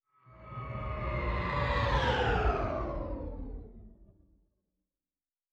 Distant Ship Pass By 3_2.wav